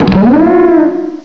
cry_not_sawsbuck.aif